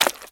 STEPS Swamp, Walk 16.wav